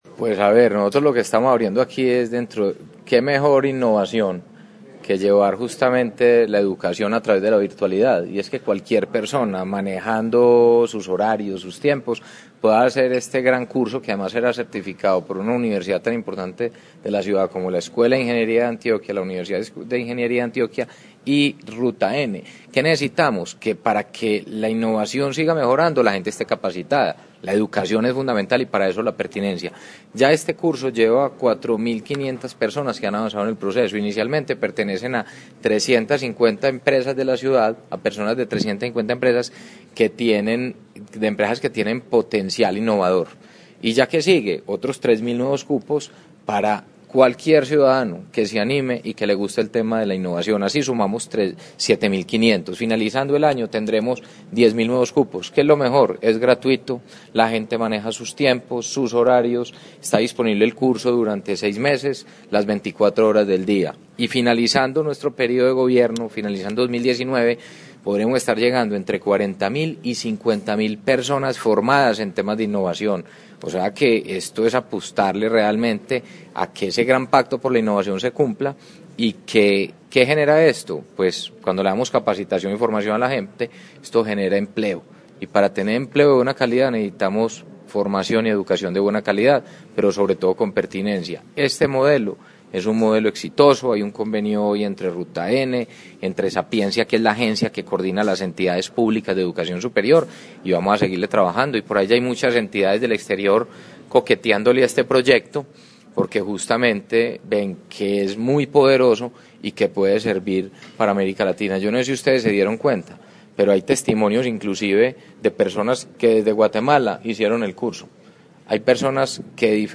• Palabras del alcalde de Medellín, Federico Gutiérrez Zuluaga